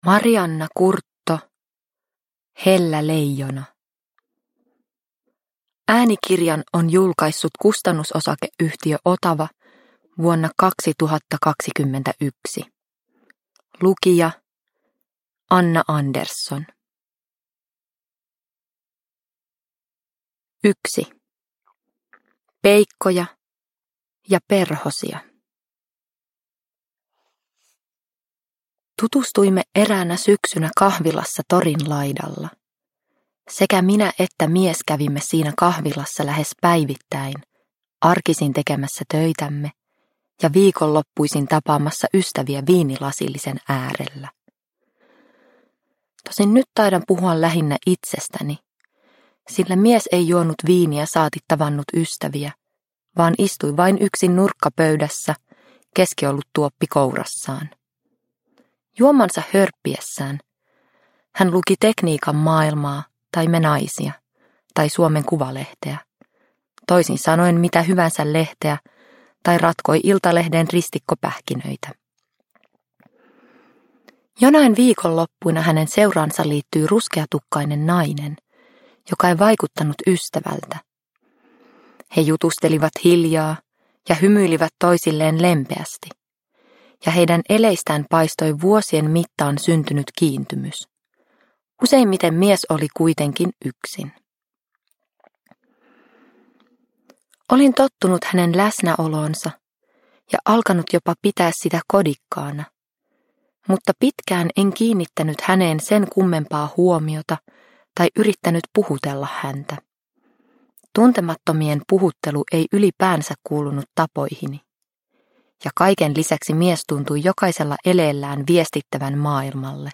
Hellä leijona – Ljudbok – Laddas ner